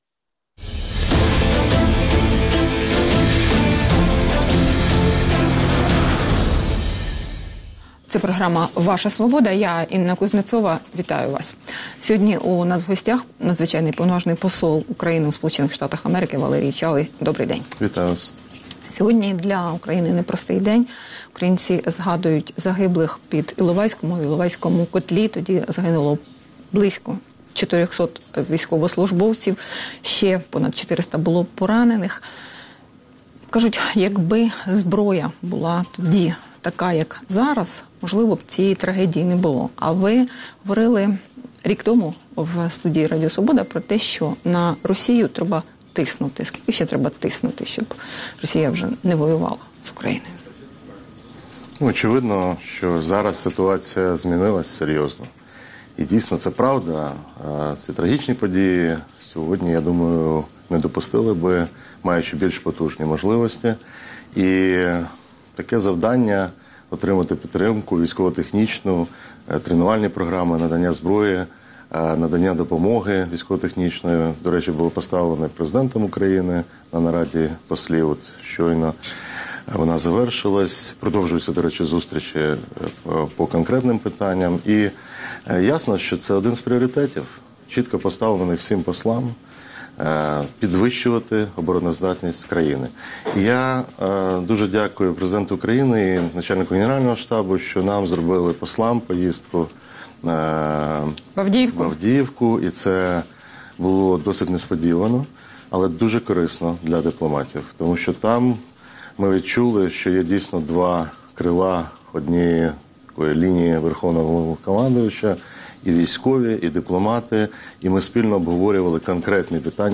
Ваша Свобода | Інтерв'ю з послом України у США Валерієм Чалим